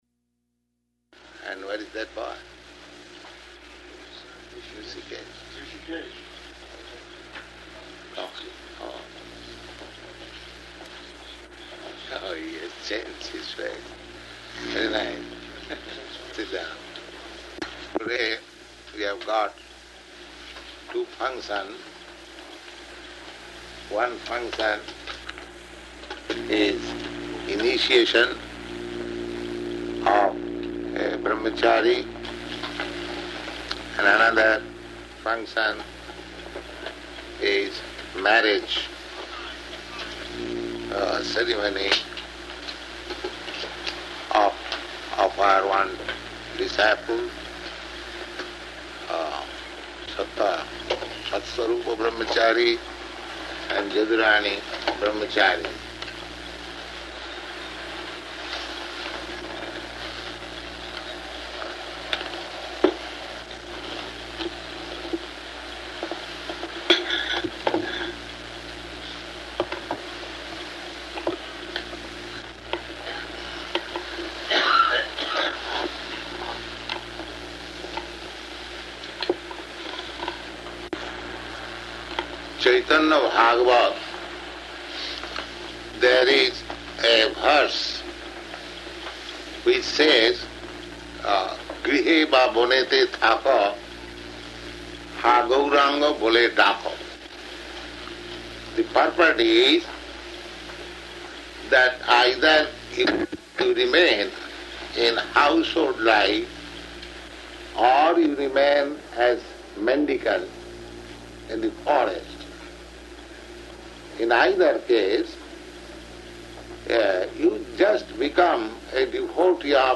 Type: Initiation
September 5th 1968 Location: New York Audio file